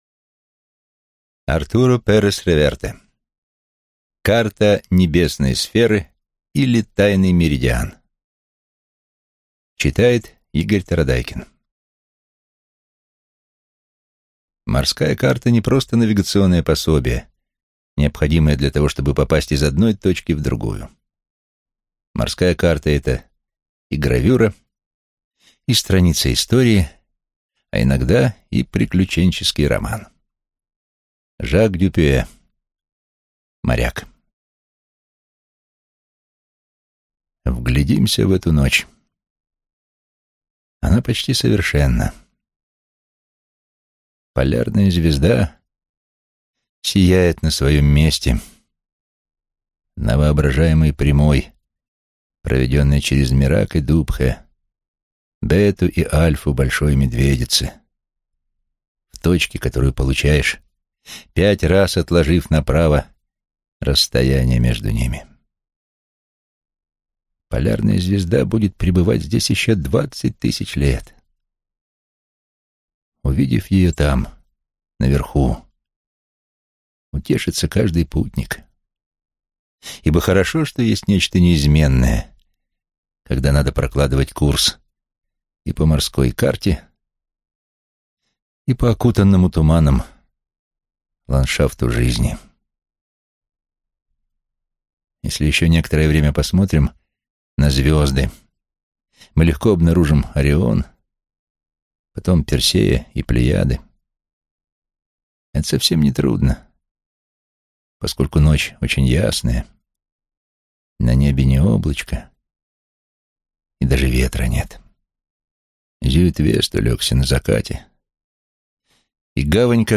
Аудиокнига Карта небесной сферы, или Тайный меридиан | Библиотека аудиокниг